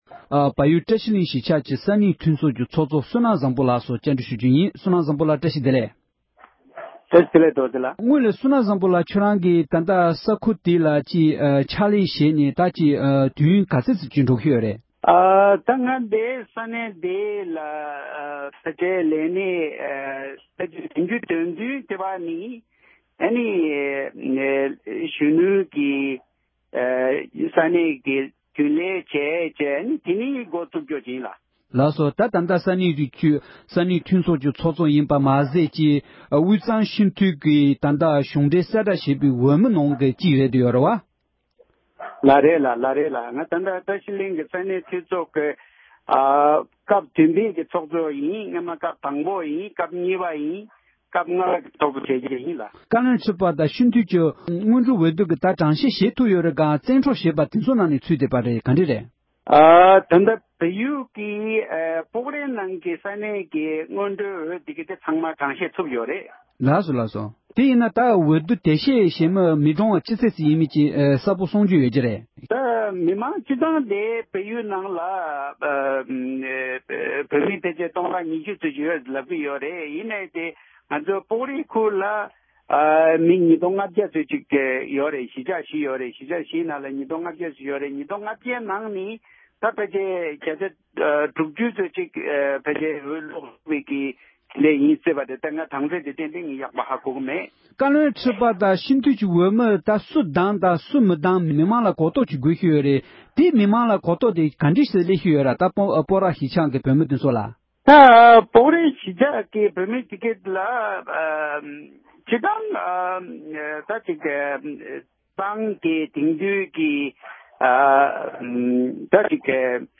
བལ་ཡུལ་པོཀ་ར་གཞིས་ཆགས་ས་བརྟན་སྐོར་གླེང་མོལ།